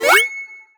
pgs/Assets/Audio/Collectibles_Items_Powerup/collect_item_18.wav at master
collect_item_18.wav